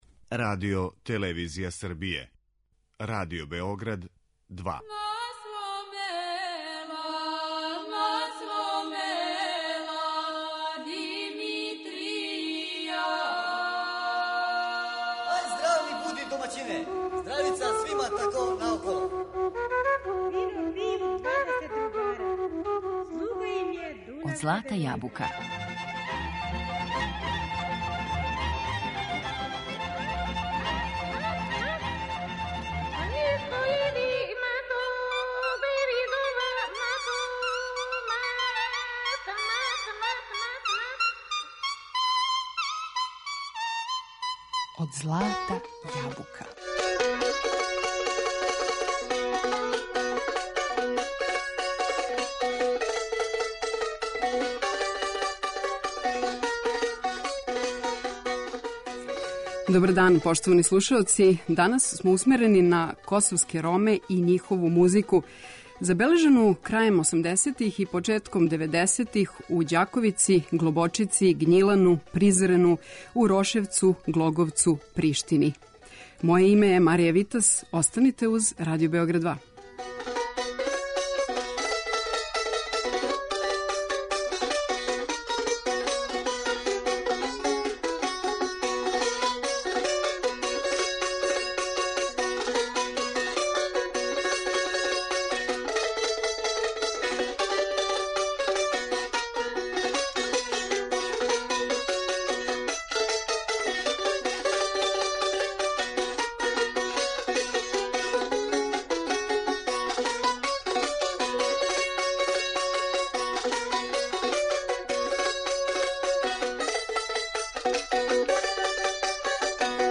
Музика косовских Рома је динамична и разноврсна, пуна традиционалног, локалног звука, као и сасвим модерних тенденција.